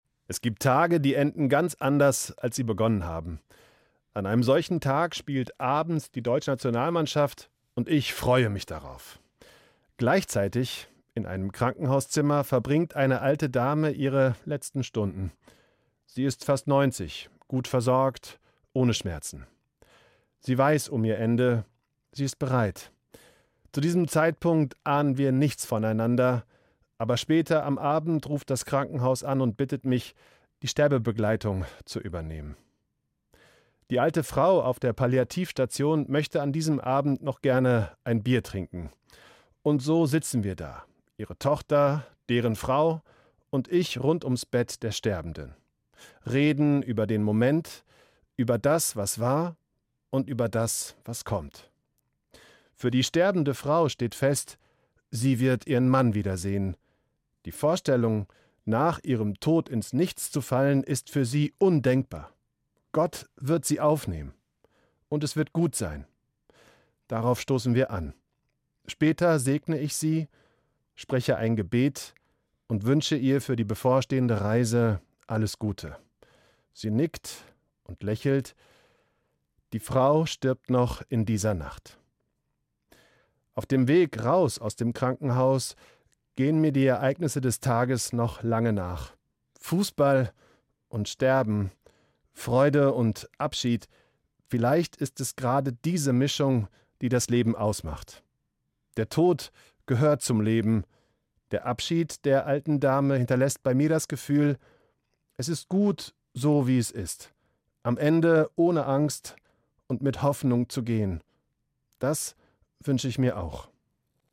Evangelischer Pfarrer, Bad Hersfeld